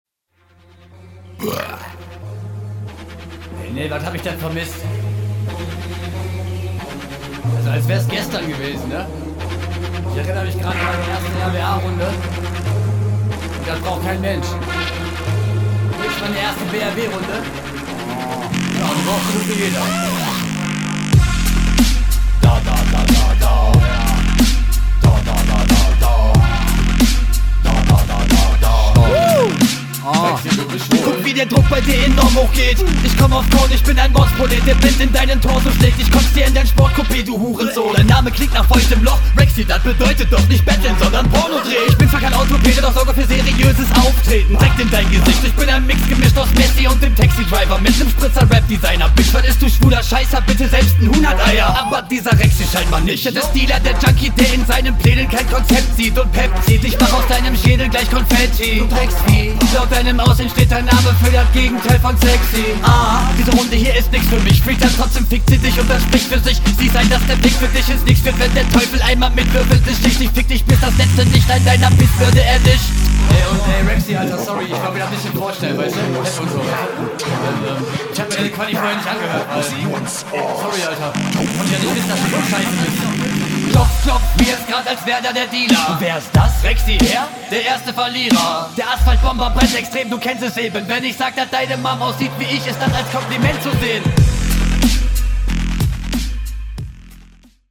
was ist dieser beat bitte für ne bombe oh junge. schön dreckiger stimmeinsatz und gut …
Beat ist erste Sahne. Guter Stimmeinsatz und dazu passende Reimketten + Flows.
Flow Variationen auch gut gemacht.